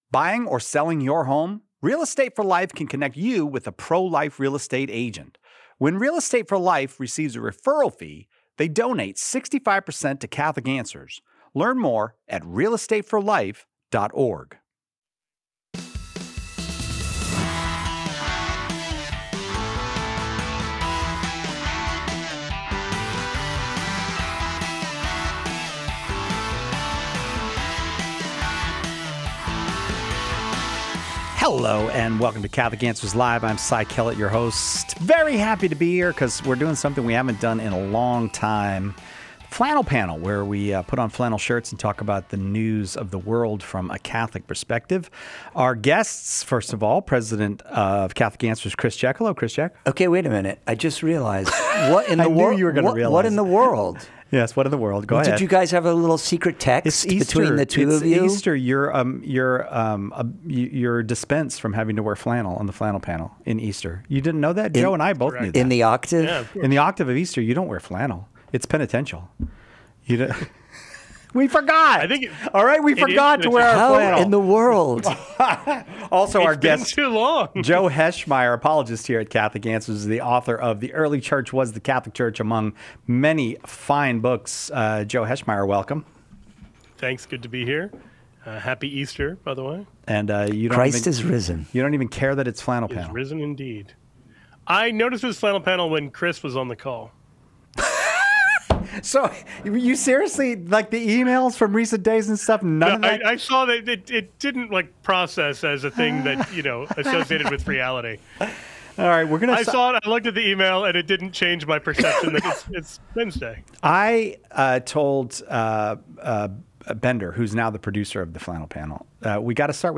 Peter Thiel Talks About the Anti-Christ in Rome - Flannel Panel